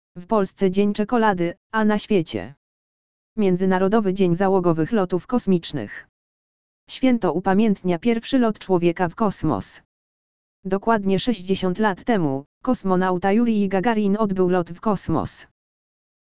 audio_lektor_miedzynarodowy_dzien_zalogowych_lotow_kosmicznych.mp3